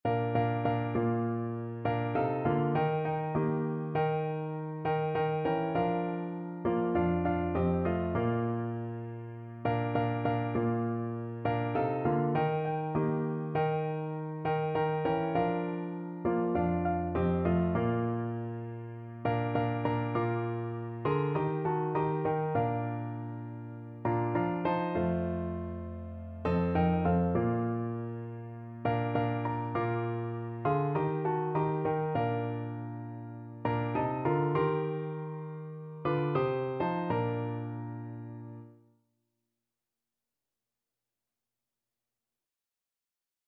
Free Sheet music for Piano Four Hands (Piano Duet)
4/4 (View more 4/4 Music)
Traditional (View more Traditional Piano Duet Music)